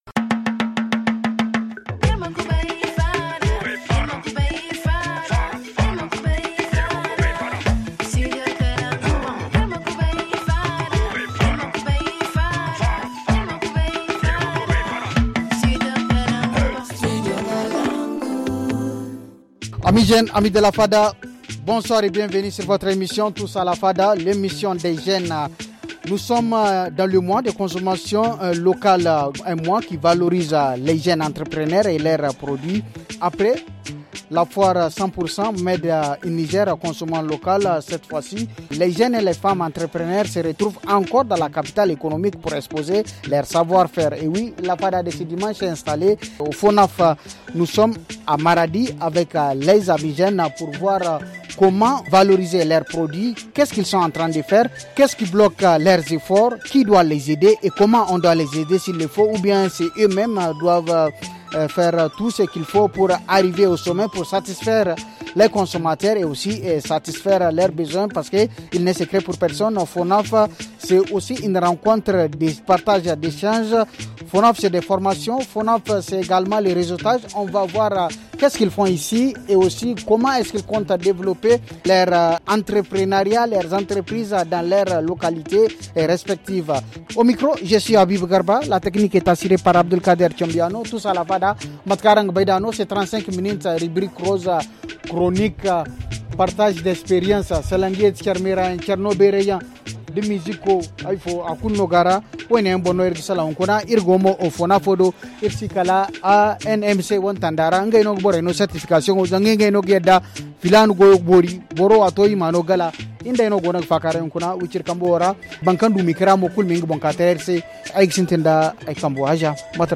Selon nos amis invités de la fada plusieurs facteurs bloquent la valorisation de leurs produits.